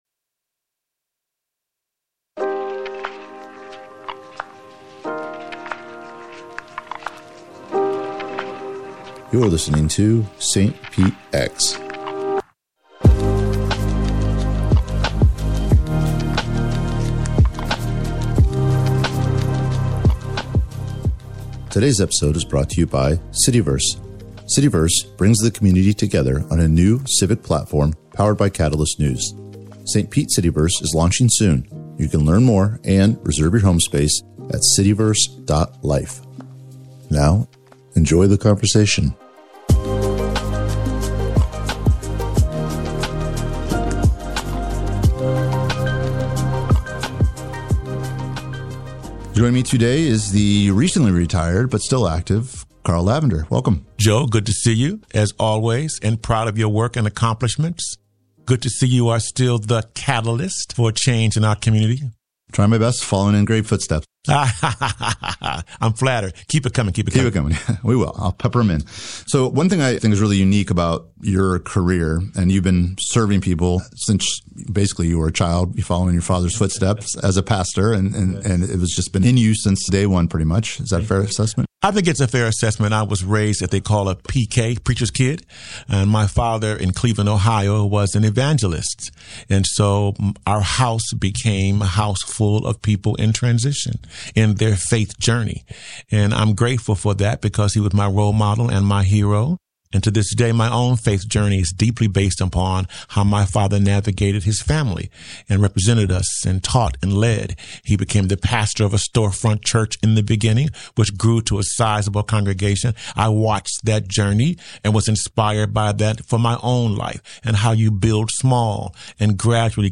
Radio St. Pete Podcast Archive